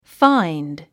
発音
fáind　ファインド
find.mp3